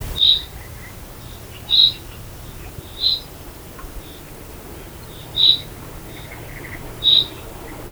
riasztohangja_poroszlo00.07.WAV